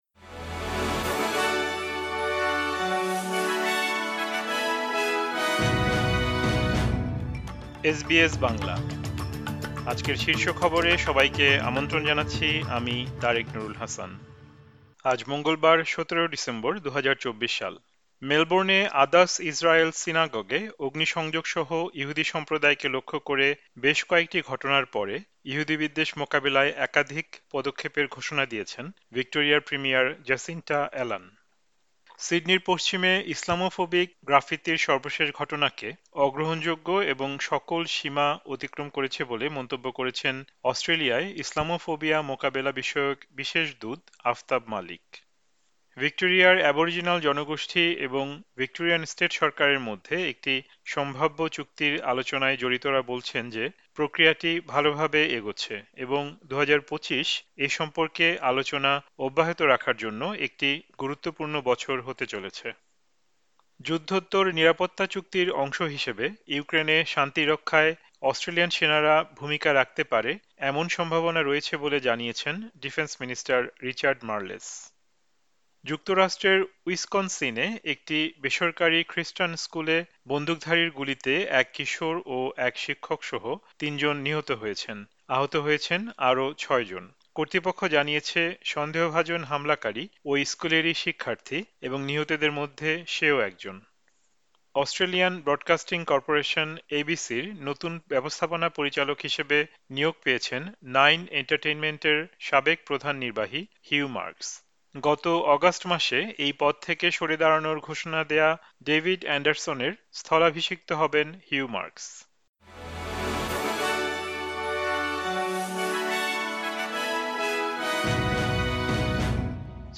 এসবিএস বাংলা শীর্ষ খবর: ১৭ ডিসেম্বর, ২০২৪।